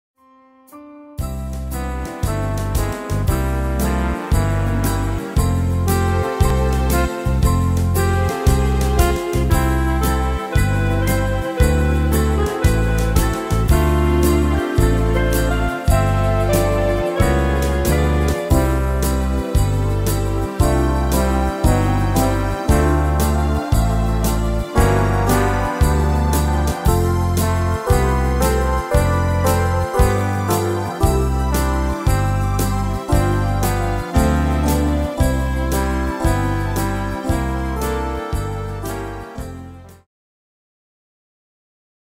Tempo: 115 / Tonart: C-Dur